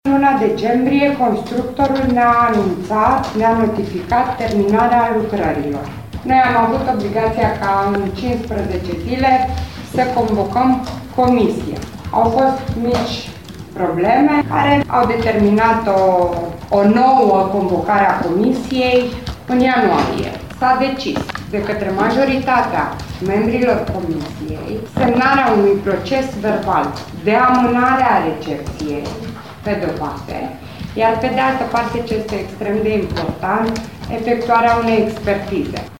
Vicepreşedintele Consiliului Judeţean, Roxana Iliescu, spune că recepţia nu se poate face fără o expertiză a lucrărilor efectuate în ultimii zece ani la clădirea care adăposteşte Muzeul Banatului.